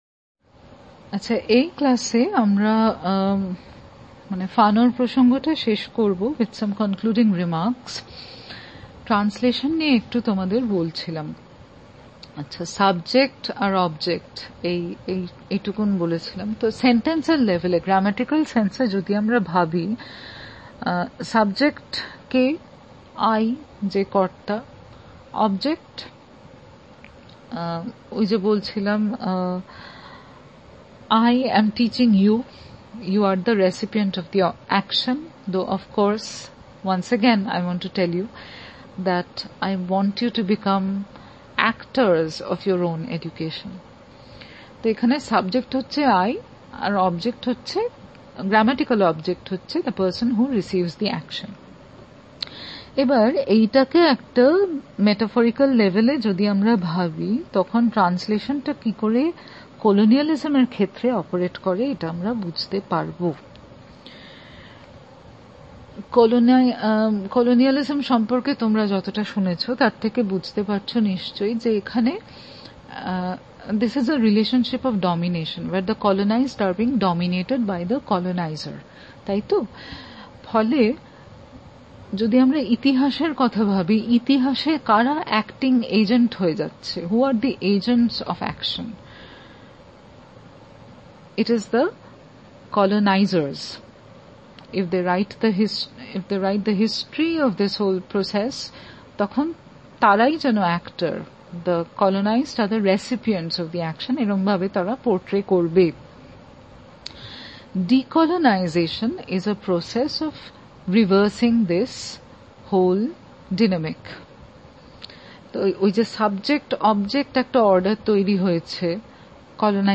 DB Lec 4 Decolonization, translation.mp3